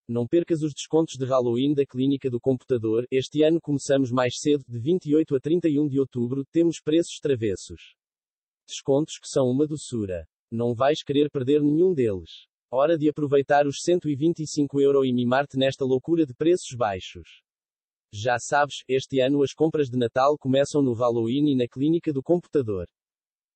mp3-output-ttsfreedotcom.mp3